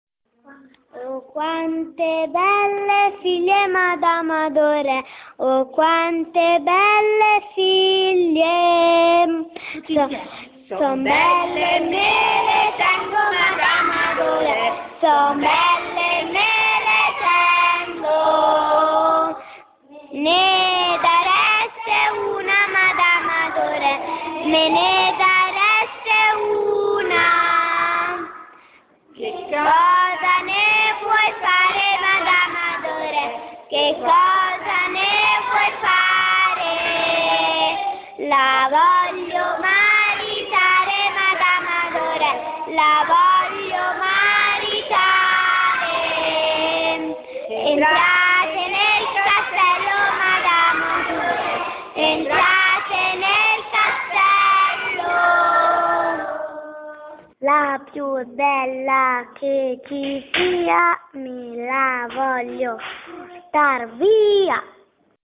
Inserito in Poesie filastrocche
(Cantata dai bambini della cl.1-Sc.Primaria L. Santucci di Castel del Piano -Gr-)